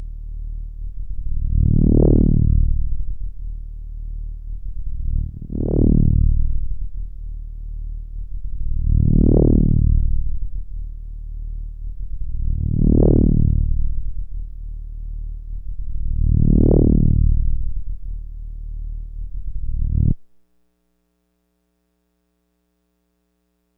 Index of /90_sSampleCDs/E-MU Producer Series Vol. 3 – Hollywood Sound Effects/Science Fiction/Scanners
SCANNER 2-L.wav